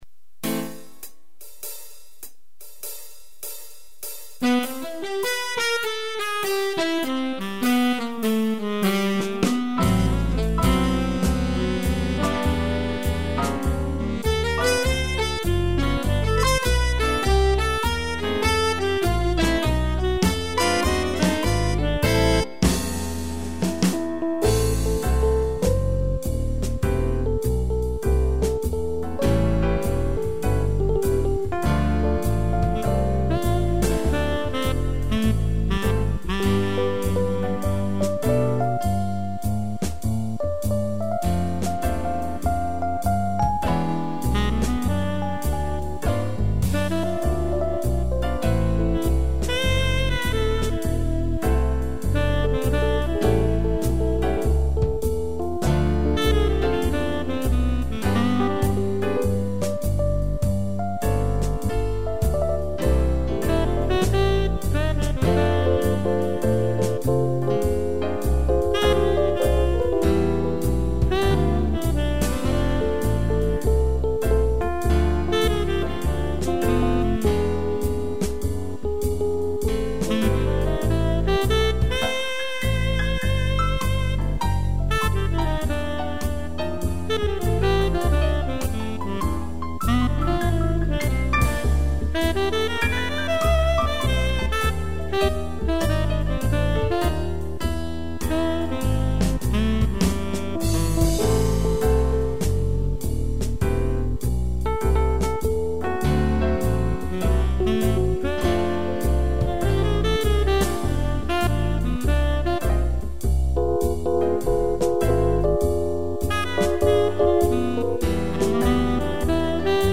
sax
instrumental